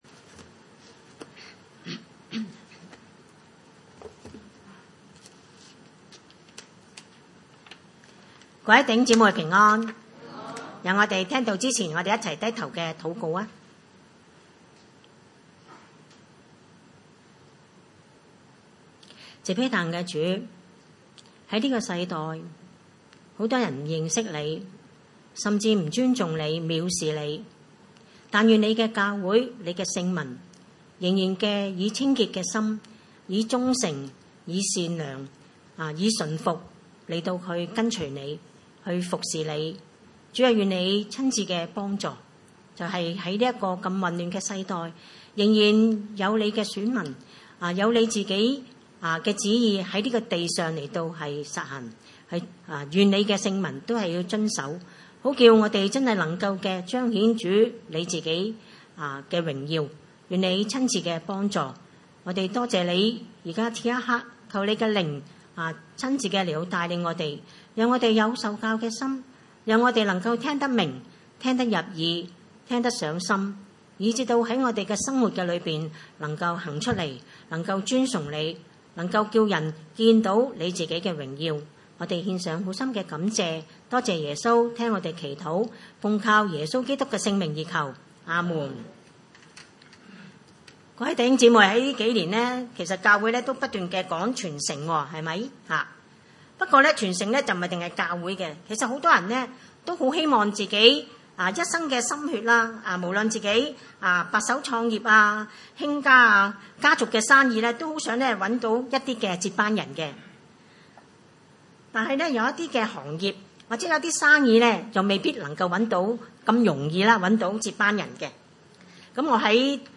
經文: 撒母耳記上八 : 1-22 崇拜類別: 主日午堂崇拜 1 撒母耳年紀老邁，就立他兒子作以色列的士師。